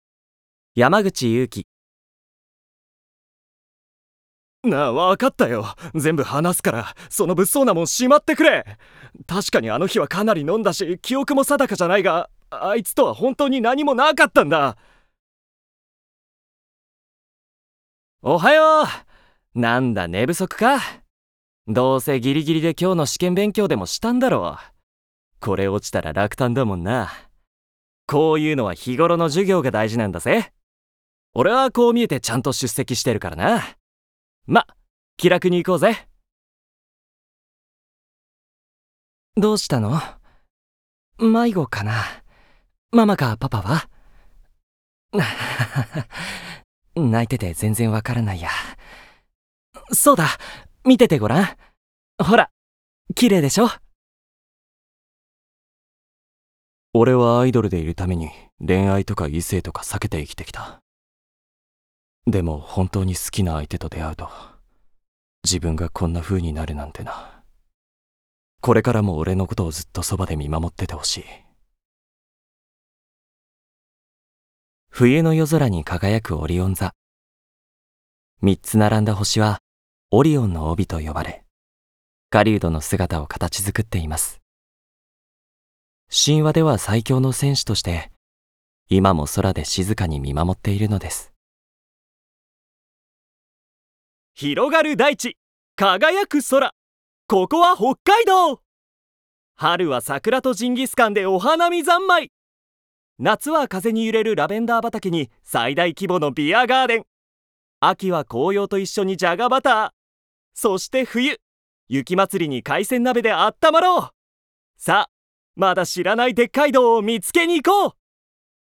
方　言　：　北海道弁
Voice Sample
ボイスサンプル